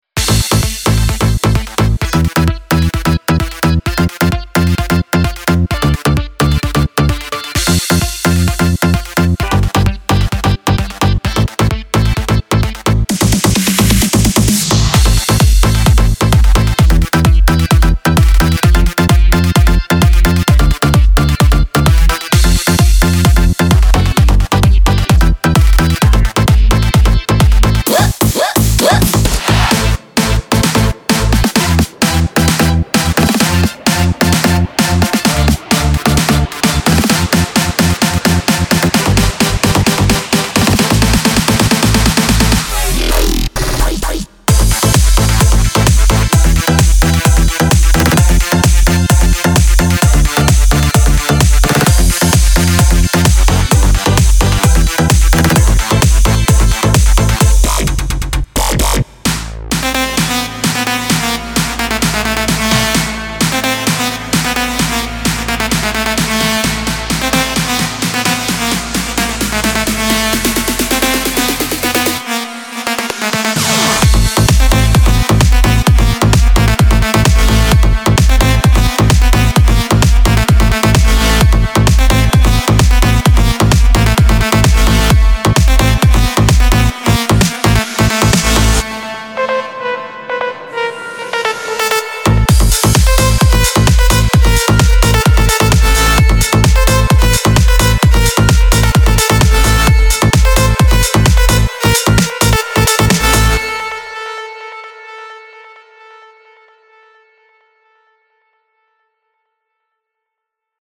מקצבים לPA 700
טראק ומקצב חדש שבניתי - זה מהמקצבים הראשונים שאני בונה, אז אשמח לתגובות בעיקר על המקצב… EDM 13 - dj 28 sv.MP3